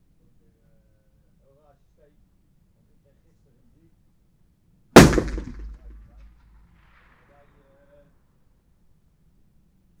01_gunshot/shot556_49_ch01_180718_162400_03_.wav · UrbanSounds/UrbanSoundsNew at main
Environmental
Streetsounds
Noisepollution